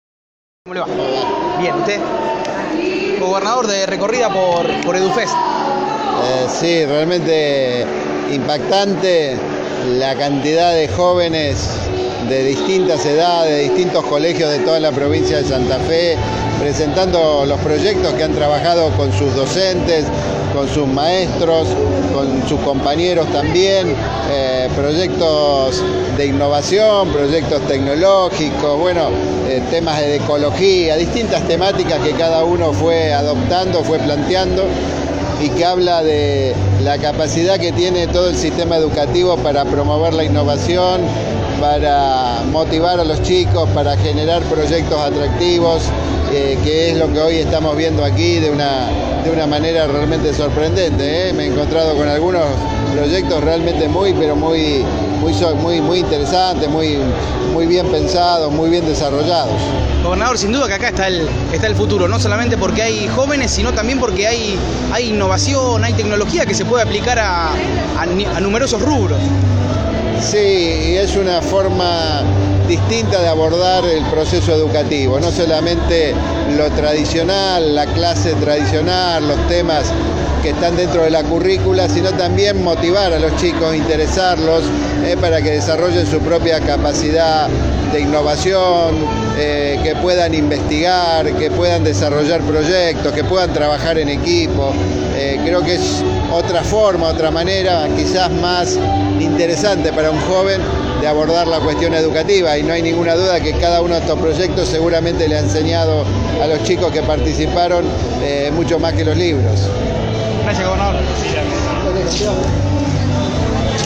Lifschitz recorrió en Rosario, EduFest 2018, el Festival de la Educación Innovadora
Palabras del gobernador